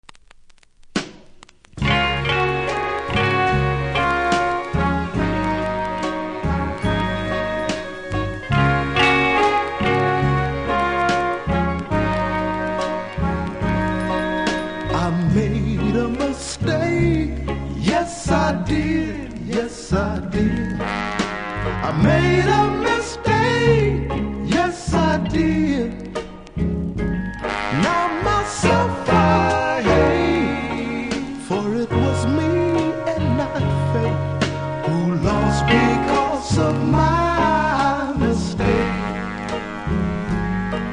〜60'S MALE GROUP